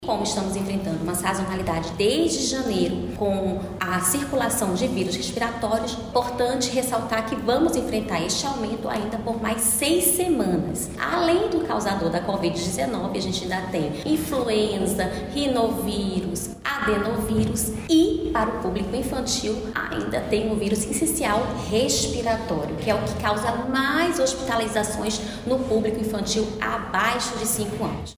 Tatyana Amorim, diretora-presidente da Fundação de Vigilância em Saúde – FVS, explica que o período de aumento das síndromes respiratórias vai se estender por algumas semanas.